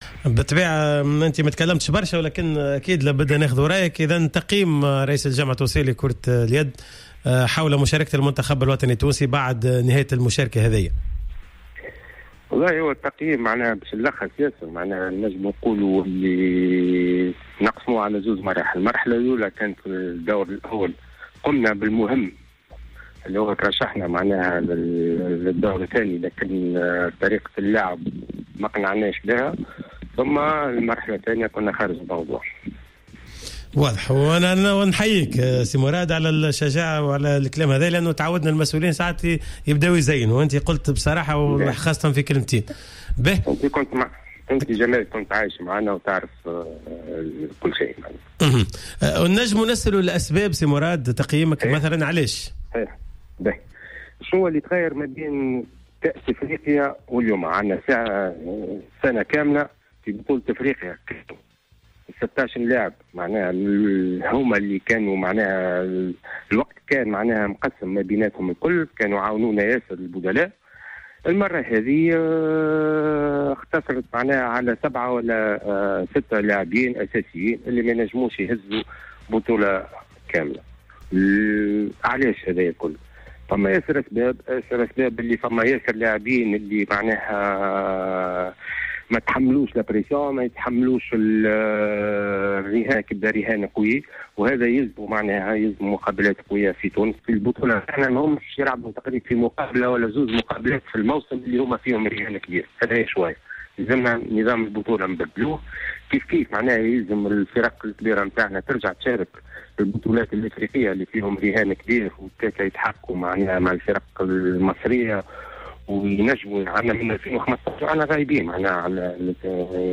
مداخلة في حصة "راديو سبور"